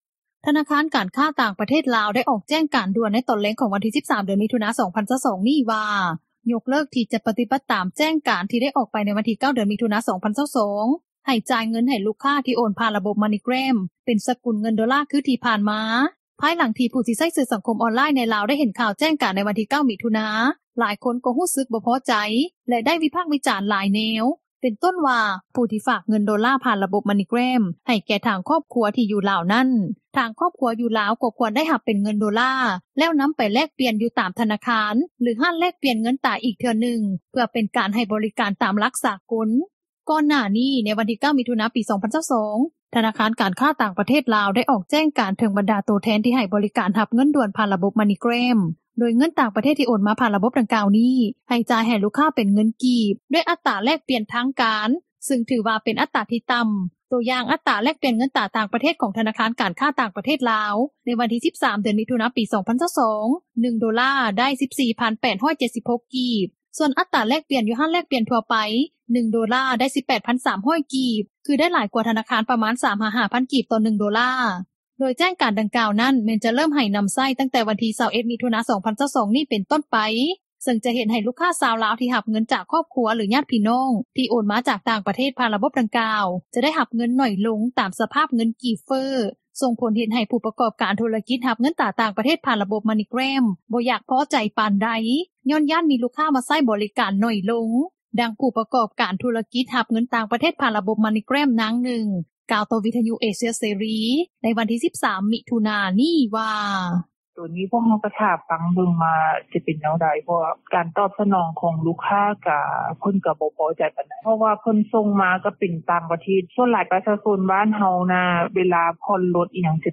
ດັ່ງຜູ້ປະກອບການທຸຣະກິຈ ຮັບເງິນຕ່າງປະຕ່າງປະເທດ ຜ່ານລະບົບ MoneyGram ນາງນຶ່ງ ກ່າວຕໍ່ວິທຍຸເອເຊັຽເສຣີ ໃນມື້ວັນທີ່ 13 ມິຖຸນາ ນີ້ວ່າ:
ດັ່ງຊາວລາວ ນາງນຶ່ງ ກ່າວວ່າ: